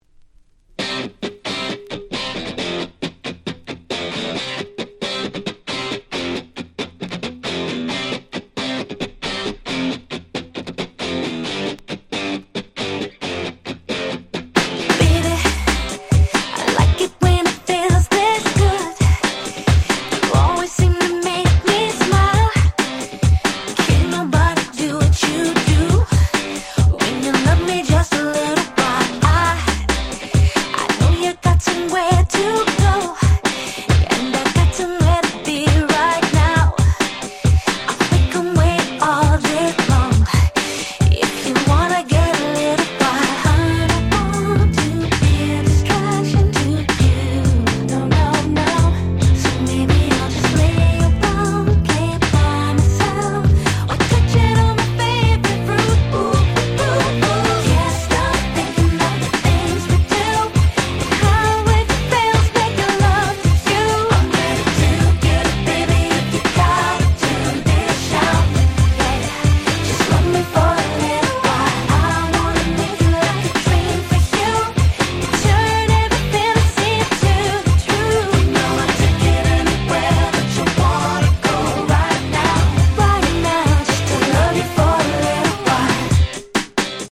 04' Smash Hit R&B !!
RockPopなキャッチーダンスナンバー！！